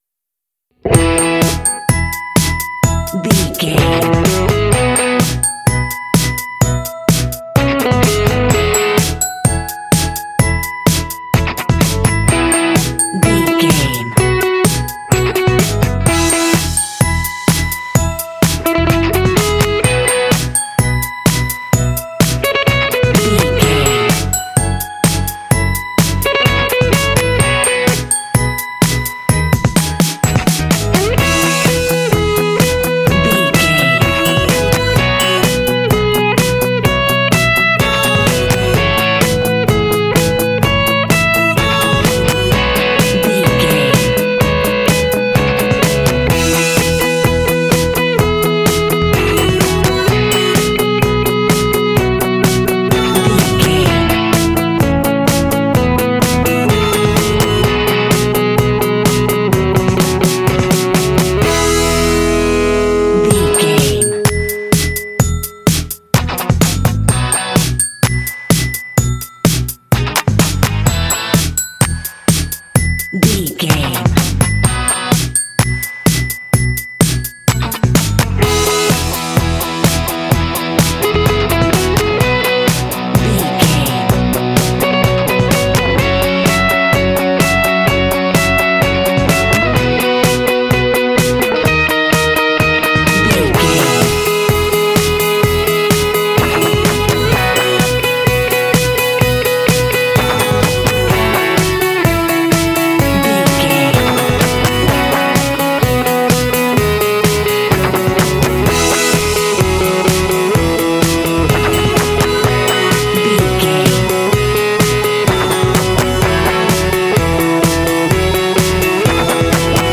Uplifting
Ionian/Major
playful
cheerful/happy
drums
electric guitar
bass guitar
synthesiser
pop
contemporary underscore
indie